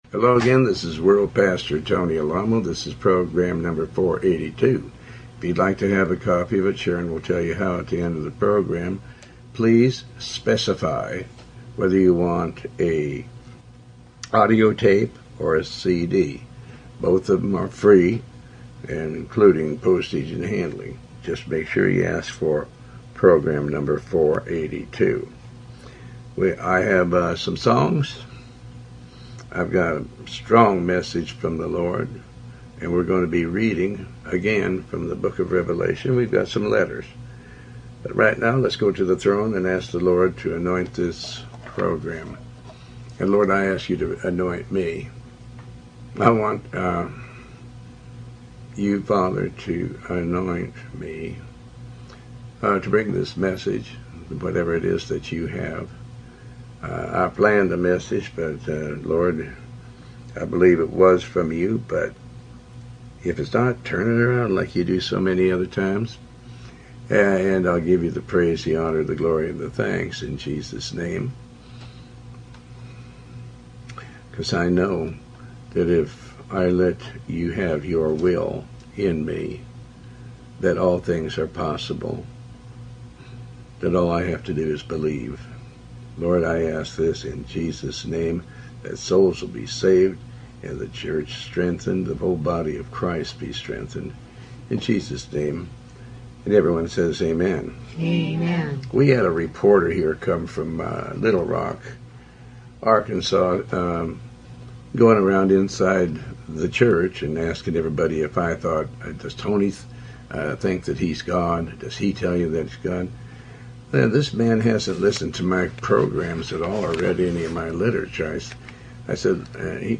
Talk Show Episode, Audio Podcast, Tony Alamo and Program482 on , show guests , about Tony Alamo with Tony Alamo World Wide Ministries, categorized as Health & Lifestyle,History,Love & Relationships,Philosophy,Psychology,Christianity,Inspirational,Motivational,Society and Culture